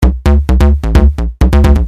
电子低音
描述：用低音合成器制作
Tag: 130 bpm Electro Loops Bass Loops 318.04 KB wav Key : Unknown